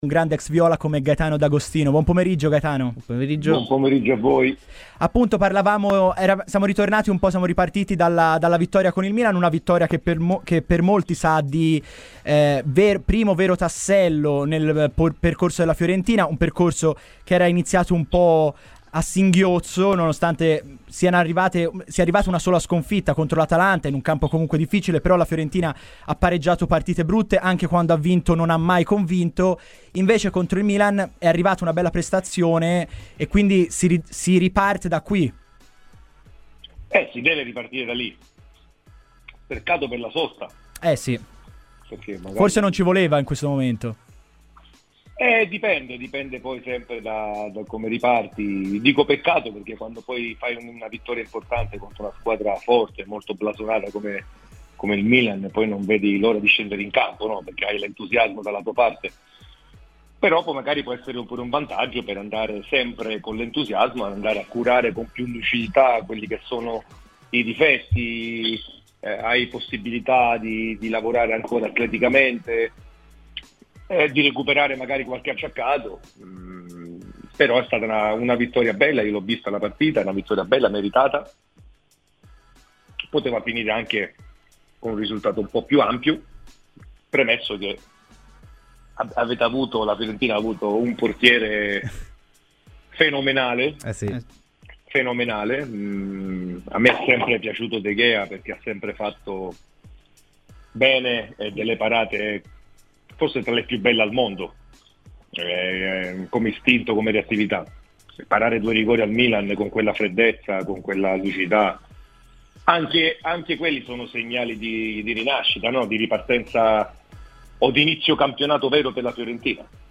L'ex viola Gaetano D'Agostino è intervenuto a Radio FirenzeViola durante "Viola Weekend" per parlare dell'attualità viola.